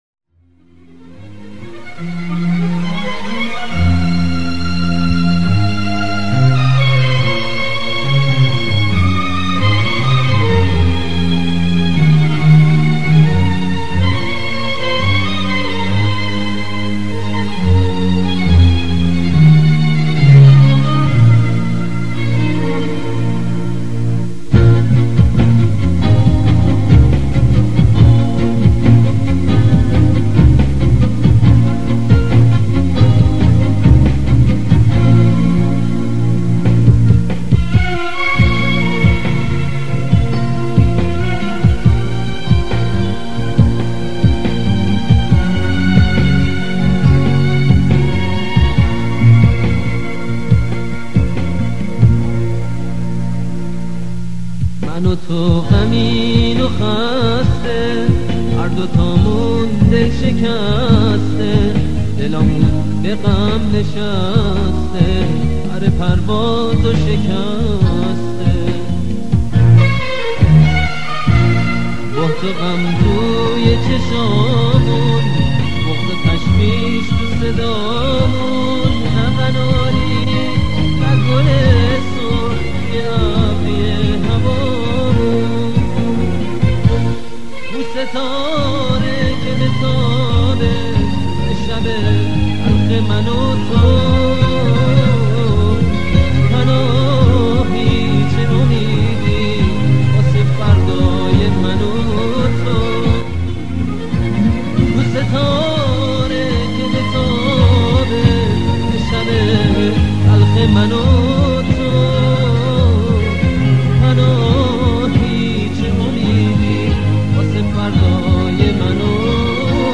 کیفیت پایین